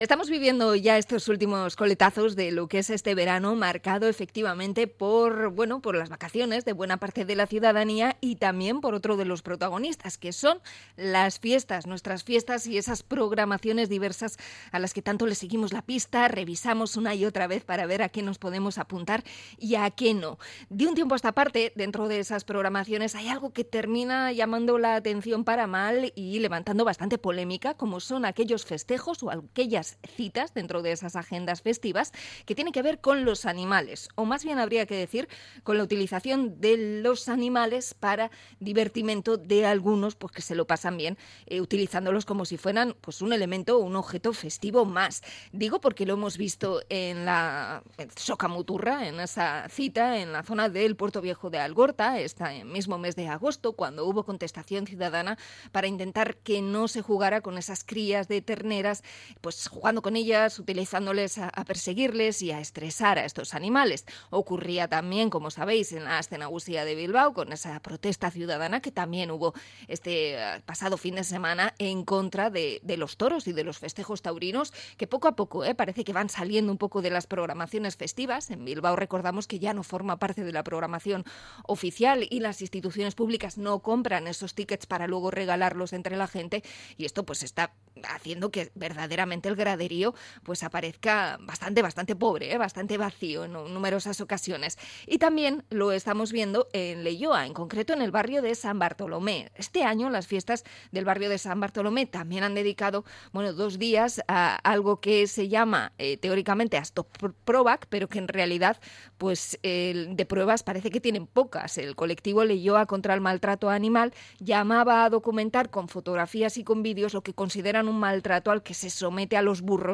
Entrevista a animalistas de Leioa contra el maltrato de las Asto Probak